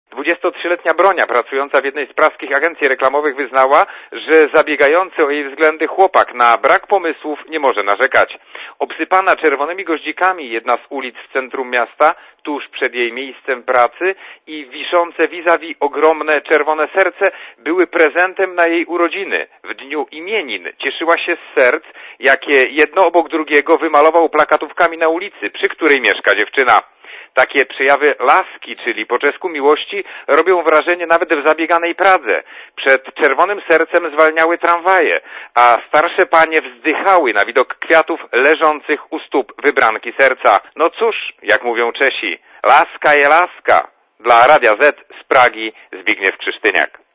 Posłuchaj relacji korespondenta Radia Zet z Pragi (183 KB)